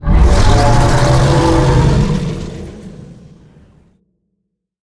Sfx_creature_hiddencroc_roar_01.ogg